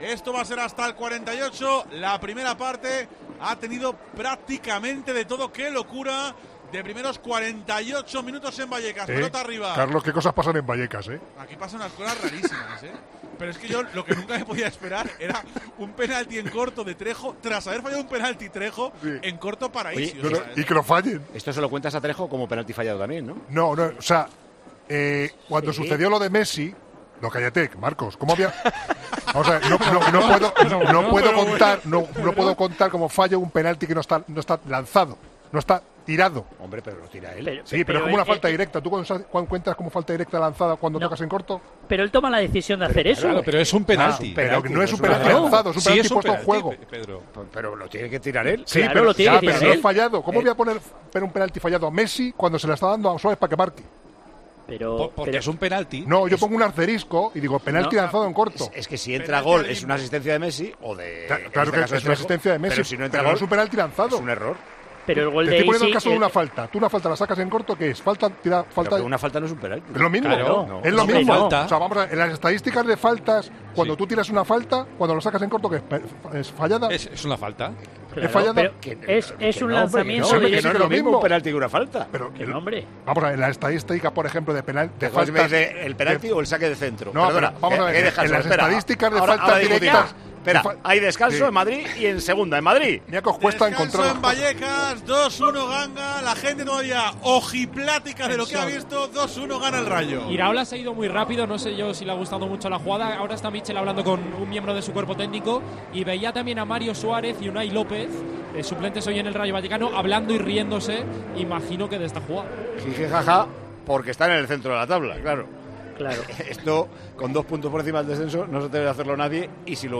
Al descanso del encuentro en 'Tiempo de Juego' se vivió una interesante discusión sobre si se tiene considerar como penalti fallado para Trejo.